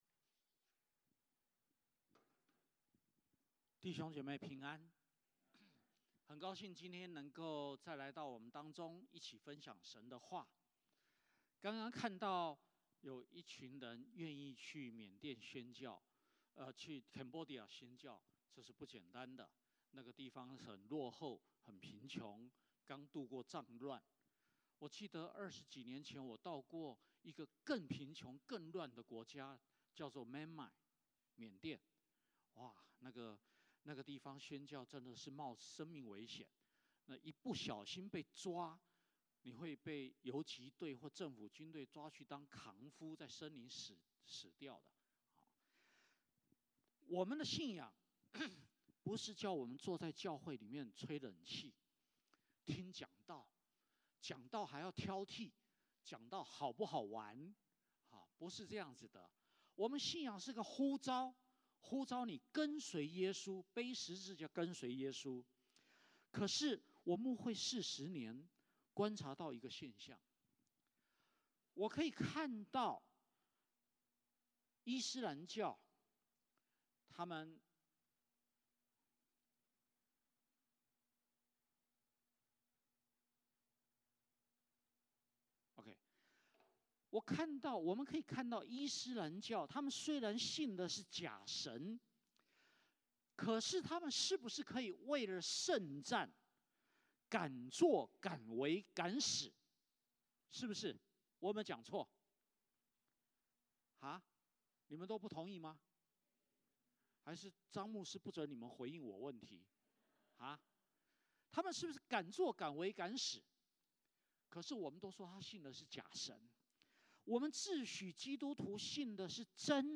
1-8 Service Type: 主日崇拜 欢迎大家加入我们的敬拜。